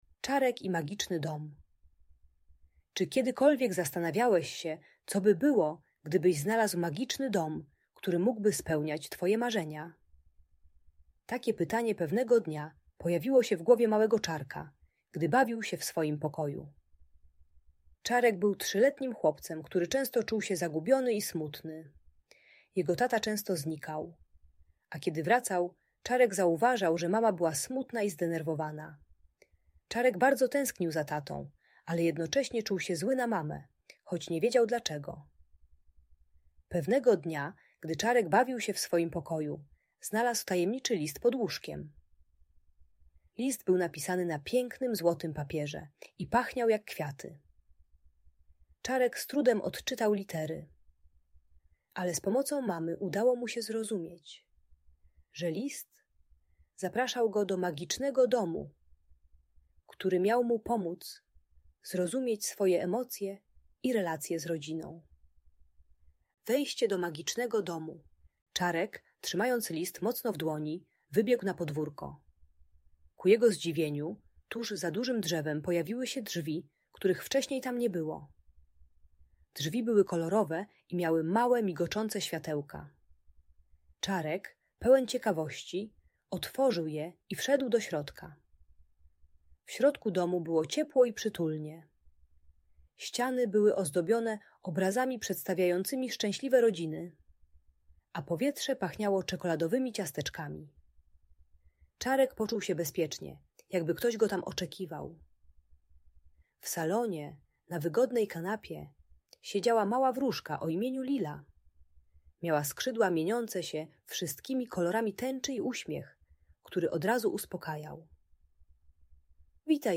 Audiobook dla dzieci o rozwodzie rodziców przeznaczony dla maluchów w wieku 3-5 lat. Ta bajka dla dziecka które przeżywa rozstanie rodziców pomaga zrozumieć trudne emocje - smutek, złość i zagubienie. Uczy technik radzenia sobie ze złością: głębokie oddychanie, bicie w poduszkę, rysowanie emocji.